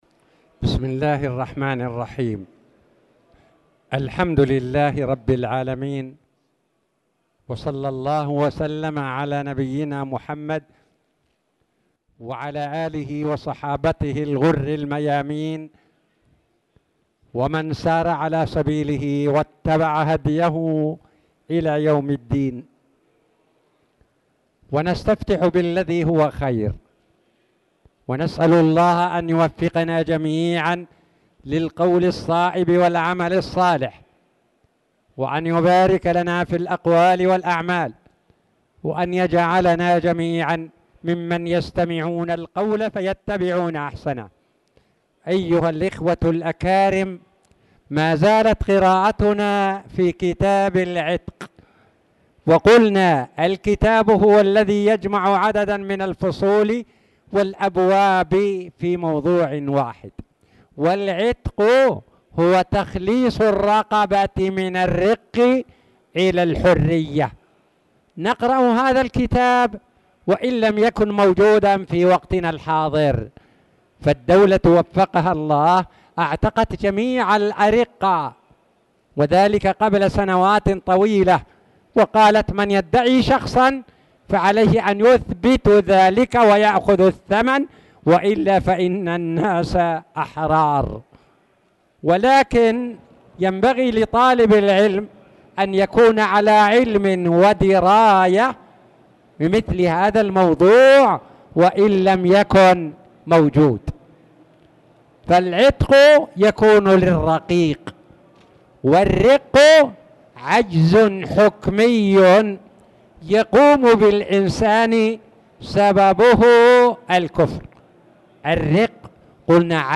تاريخ النشر ٤ محرم ١٤٣٨ هـ المكان: المسجد الحرام الشيخ